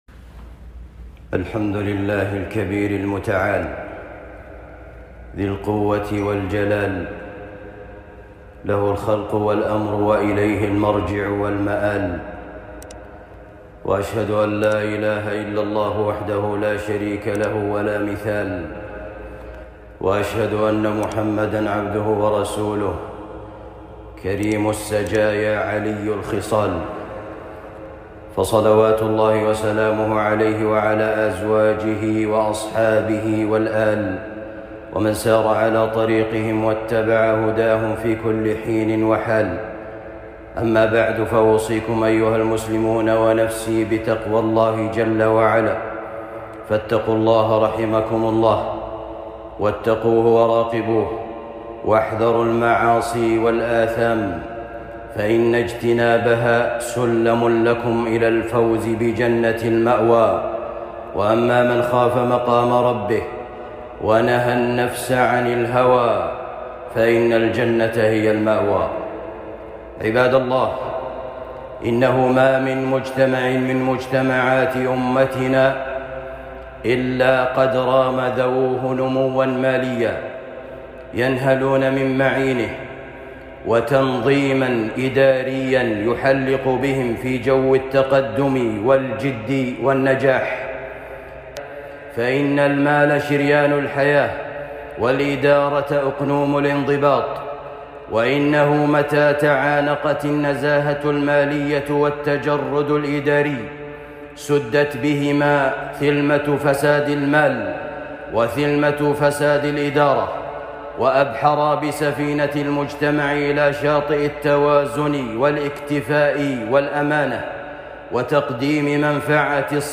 خطبة جمعة بعنوان سبيل الرشاد في مكافحة الفساد